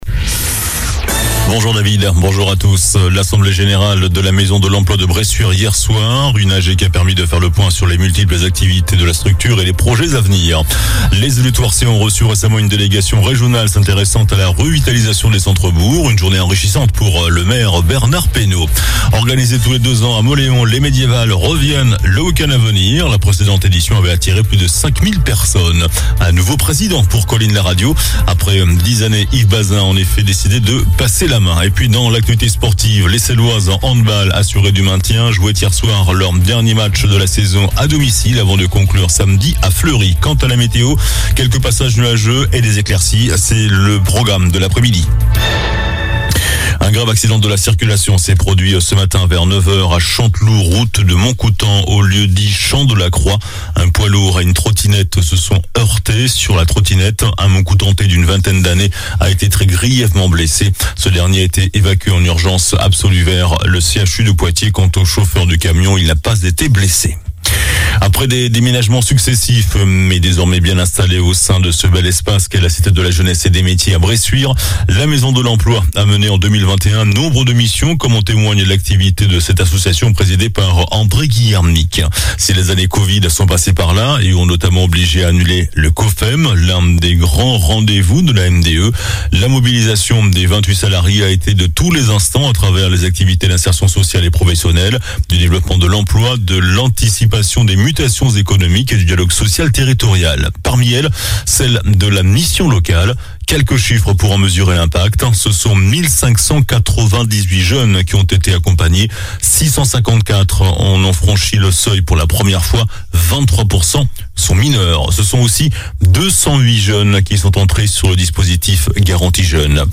JOURNAL DU JEUDI 19 MAI ( MIDI )